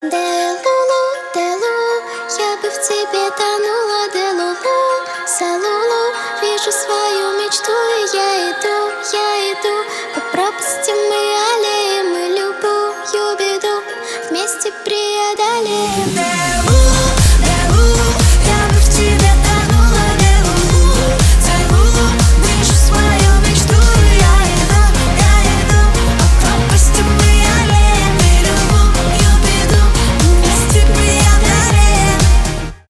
поп
детский голос